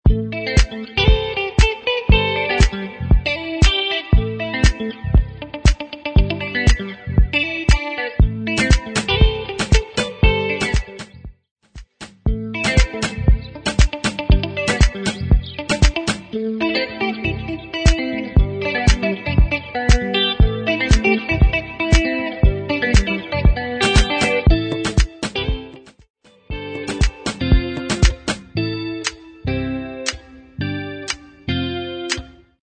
Indie Electronic Pop